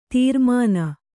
♪ tīrmāna